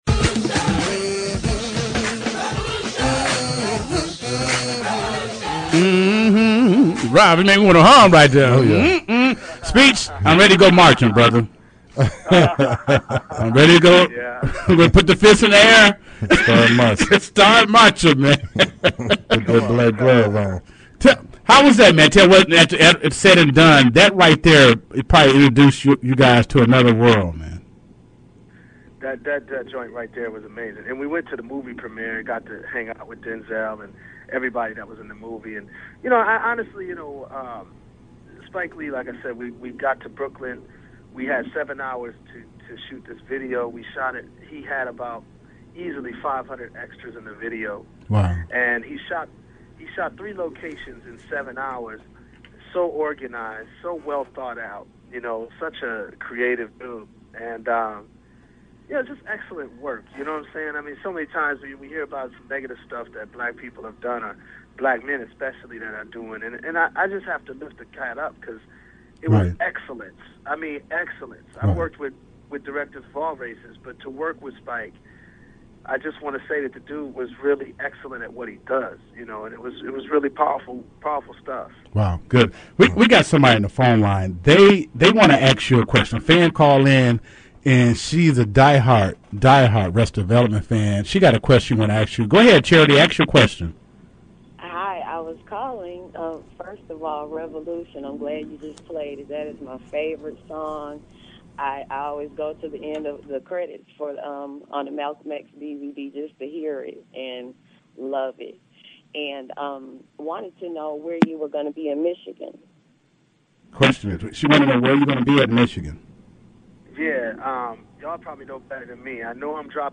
Interview with Speech from Arrested Development talks about new music and his thoughts on community and consciousness.